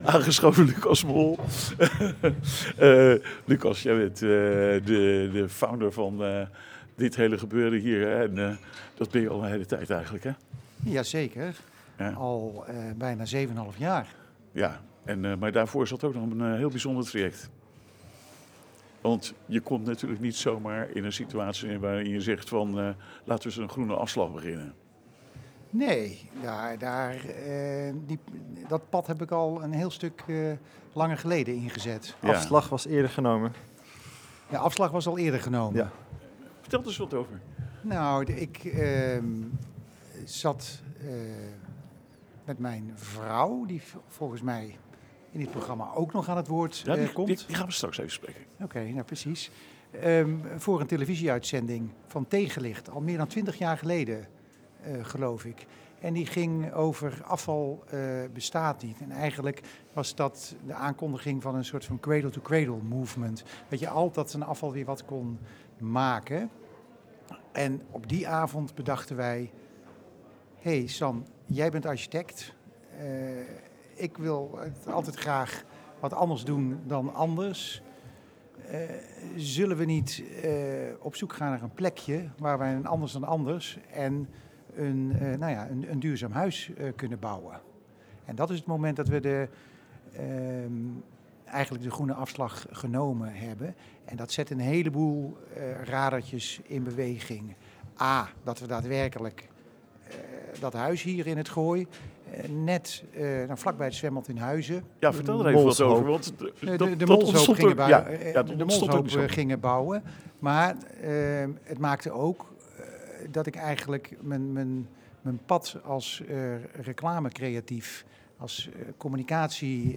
Luister naar dit deelinterview opgenomen tijdens de live-uitzending van 17 november 2025.